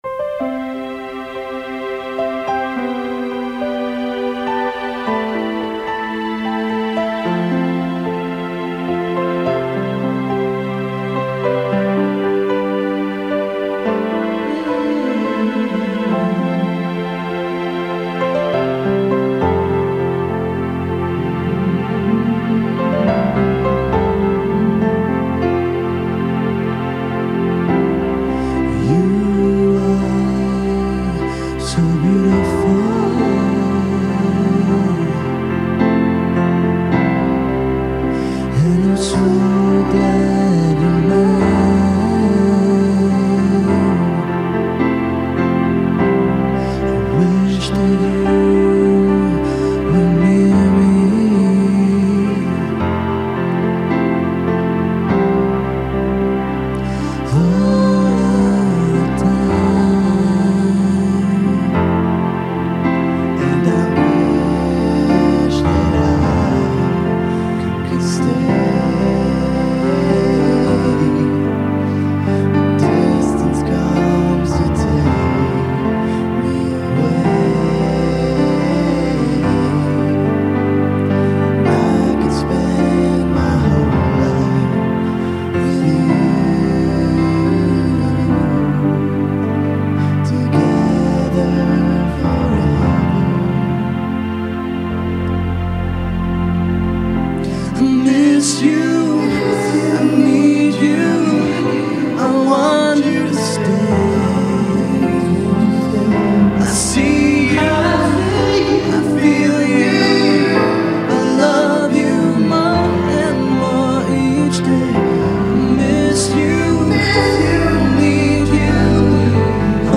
band studio album